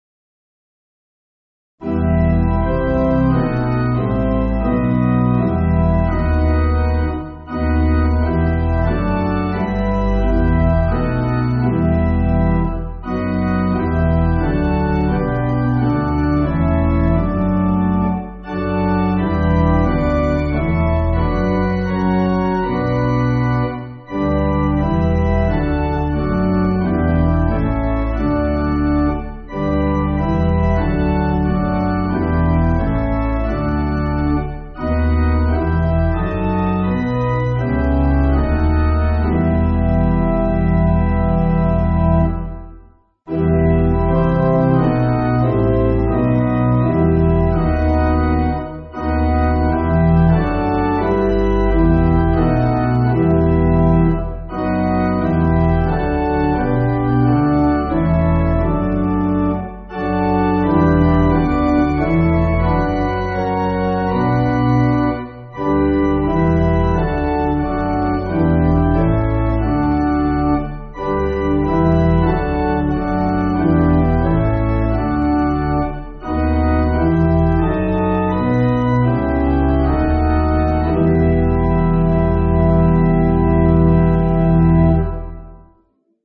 Organ
(CM)   2/Em